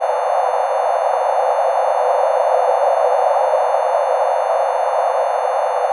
Klanglich interessant sind auch die subharmonischen Primzahlreihen selbst:
oder logarithmisch: